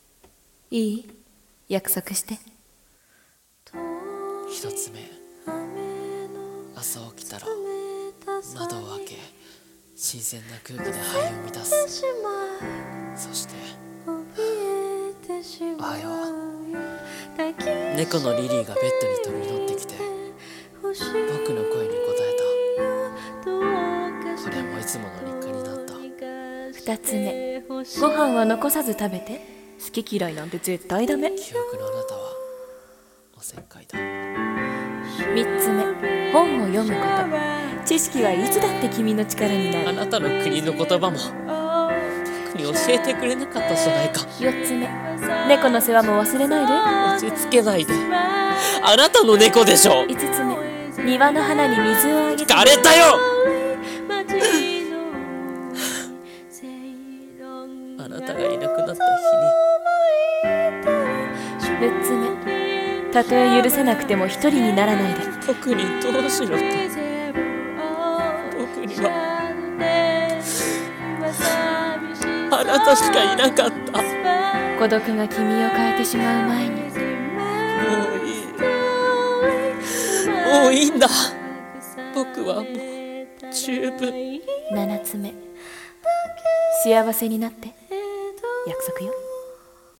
二人声劇【最後の魔女と7つの約束】